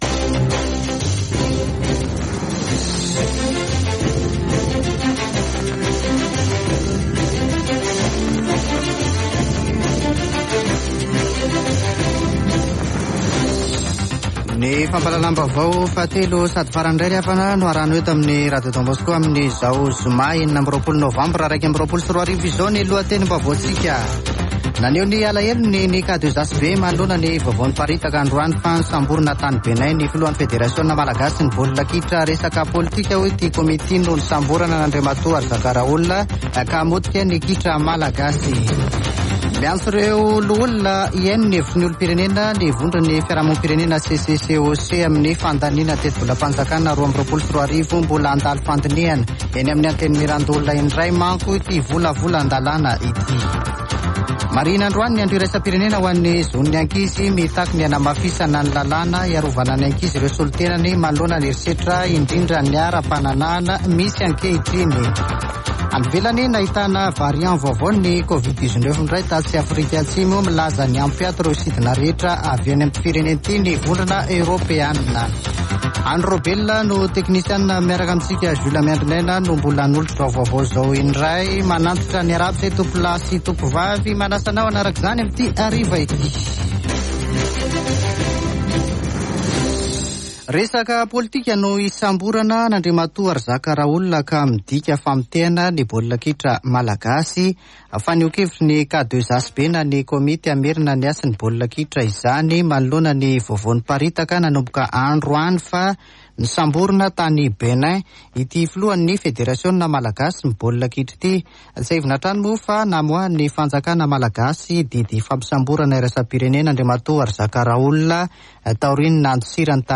[Vaovao hariva] Zoma 26 novambra 2021